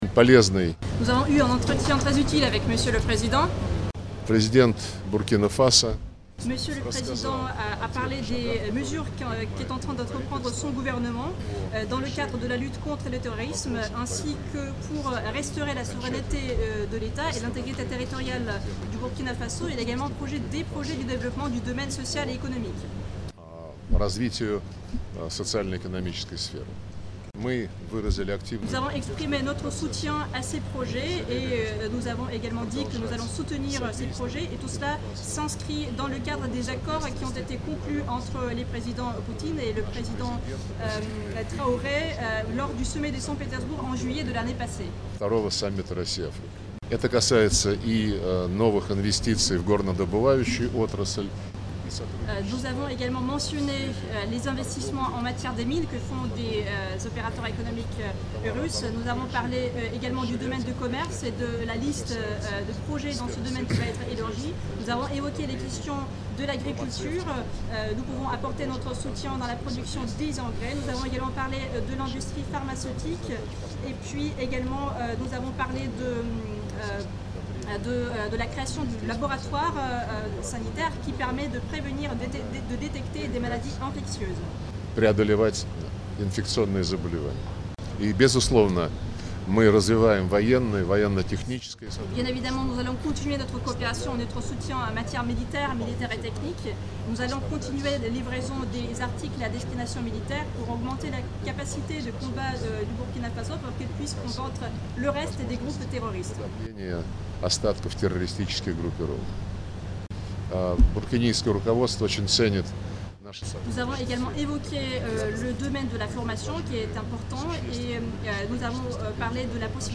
Sergueï Lavrov s’exprimant au sortir des échanges⤵
AUDIO-Intervention-du-Ministre-russe-des-Affaires-etrangeres.mp3